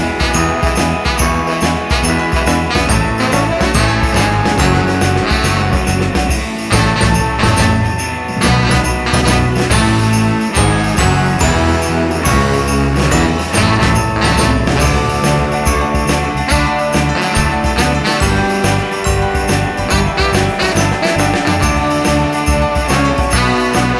no Backing Vocals Christmas 4:10 Buy £1.50